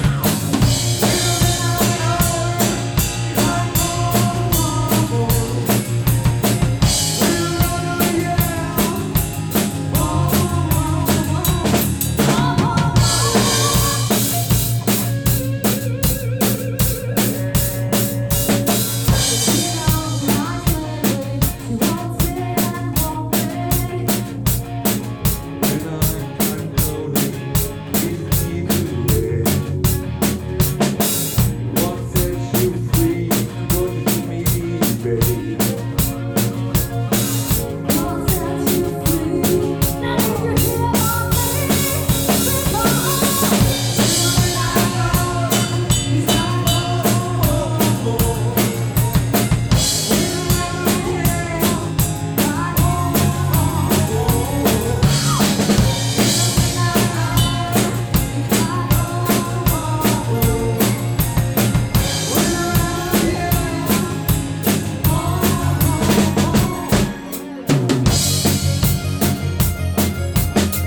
Hörproben (LIVE)